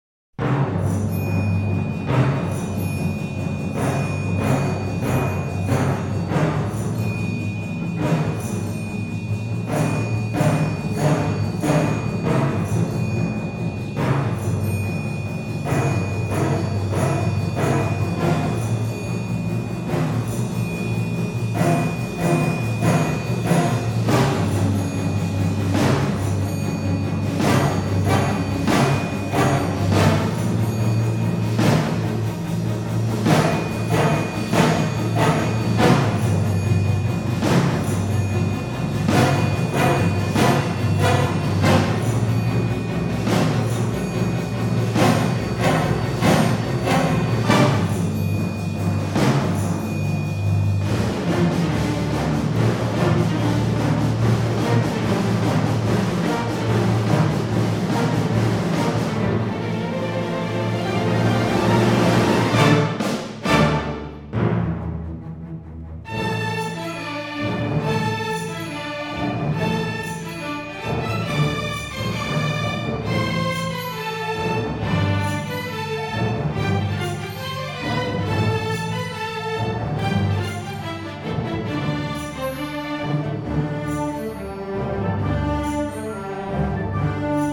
诡诵多变、气势惊人